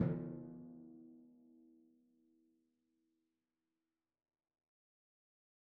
Timpani4_Hit_v3_rr1_Sum.wav